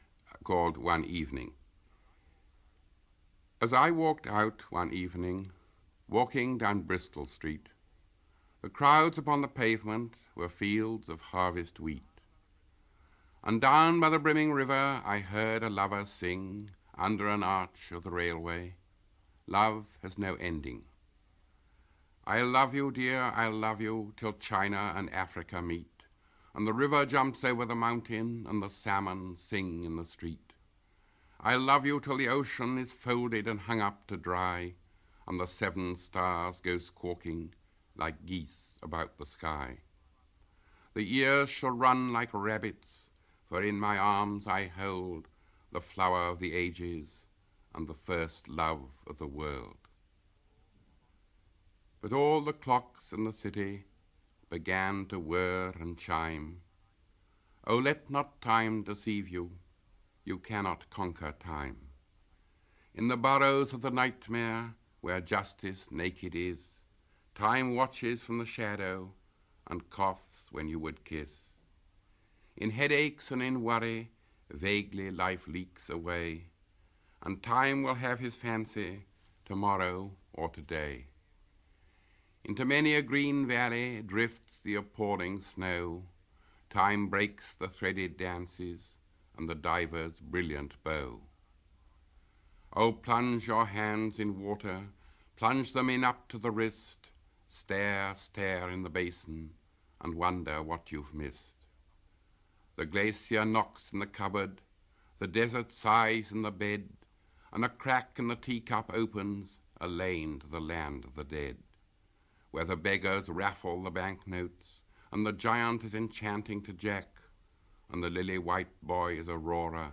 Auden lee a Auden: